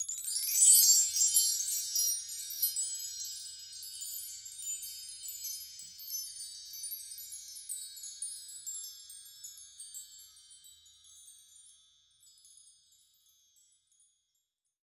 WW CHIMES.wav